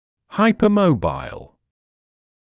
[ˌhaɪpə’məubaɪl][ˌхайпэ’моубайл]гипермобильный